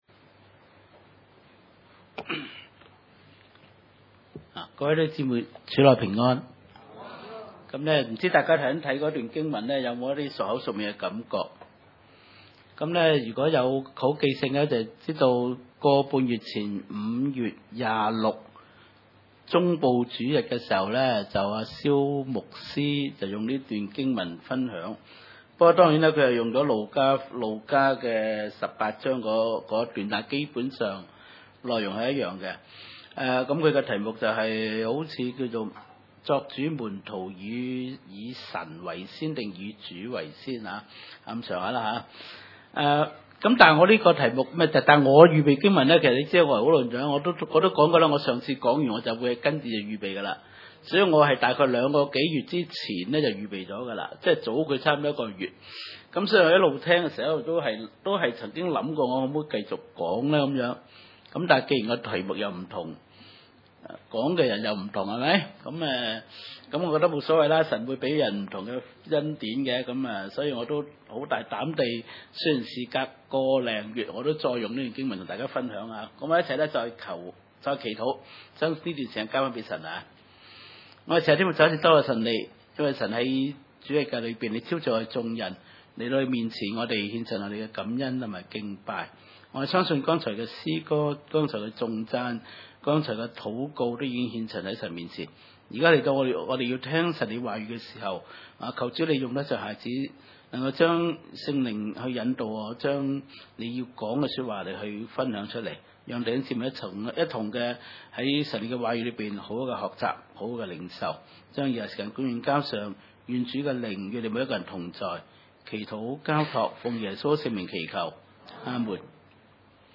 可十 17-27 崇拜類別: 主日午堂崇拜 17 耶 穌 出 來 行 路 的 時 候 、 有 一 個 人 跑 來 、 跪 在 他 面 前 問 他 說 、 良 善 的 夫 子 、 我 當 作 甚 麼 事 、 纔 可 以 承 受 永 生 。